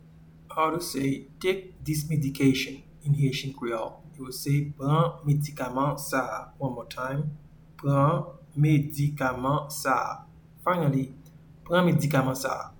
Pronunciation:
Take-this-medication-in-Haitian-Creole-Pran-medikaman-sa-a.mp3